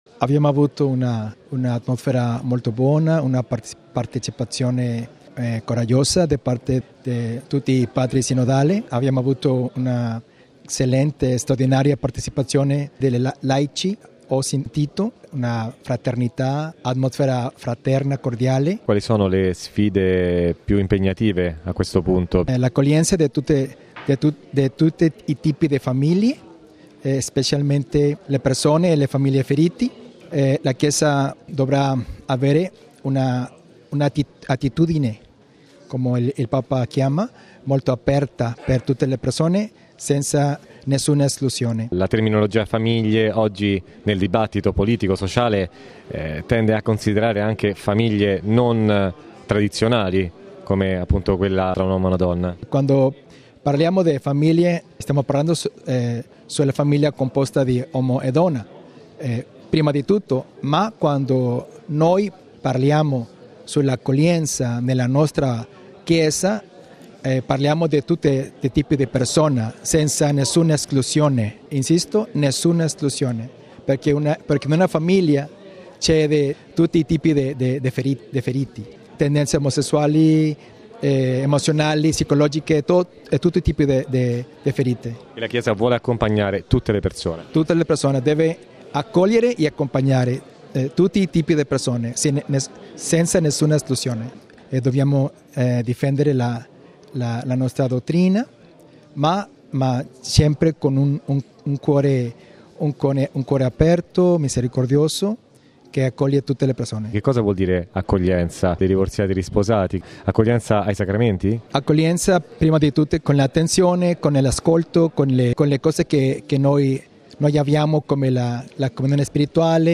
La Chiesa non esclude nessuno, è accogliente verso tutti: è quanto ha detto al Sinodo mons. Alfonso Gerardo Miranda Guardiola, vescovo di Idicra in Messico.